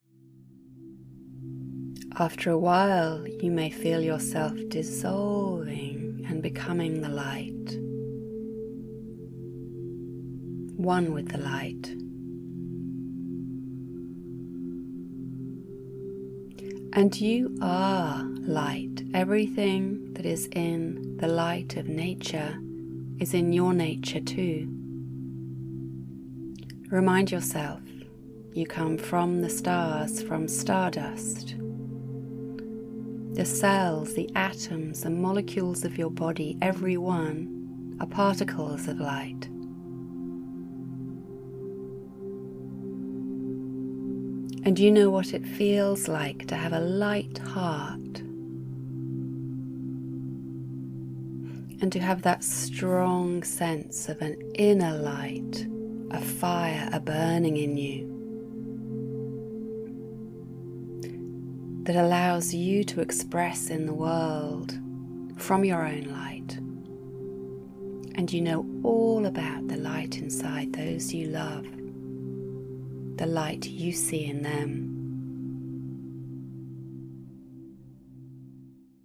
The album contains six guided meditations, each from 16 to 24 minutes long, plus a brief recording on how to use the meditations.